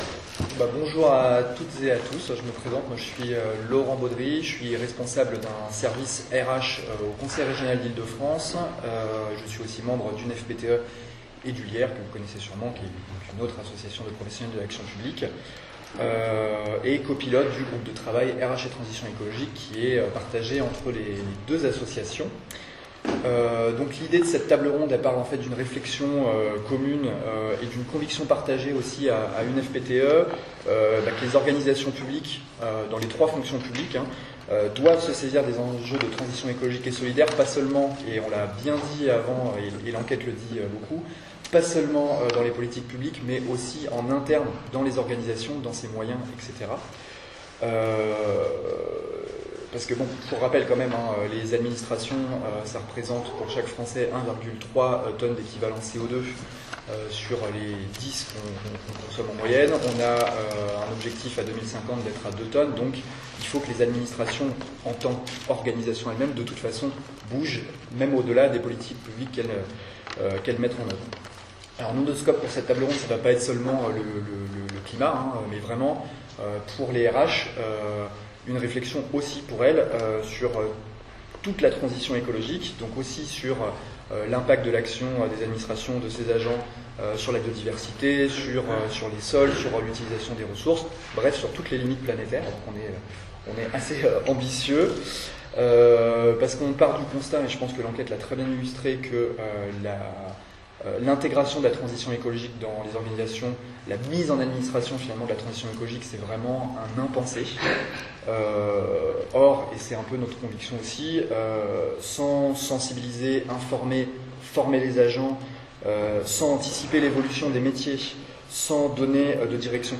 Podcast de la table ronde « La fonction RH et son rôle en matière de transition écologique dans les organisations publiques « – Une Fonction publique pour la transition écologique